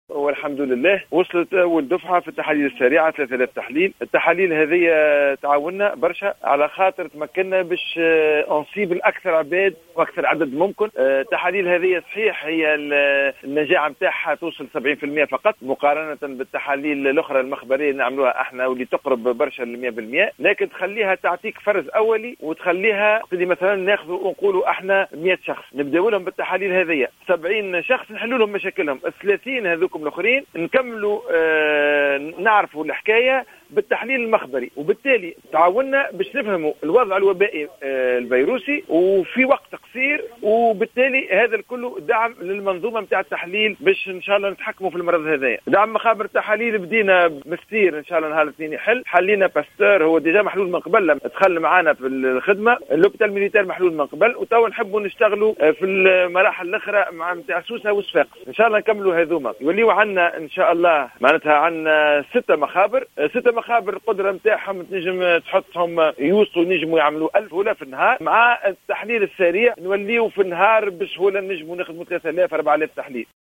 أكد المدير العام للرعاية الصحية الأساسية شكري حمودة، في تصريح للجوهرة أف أم، أن تونس تسلمت اليوم الجمعة الدفعة الأولى المكوّنة من 3000 جهاز تحليل سريع للتقصي عن فيروس كورونا المستجد.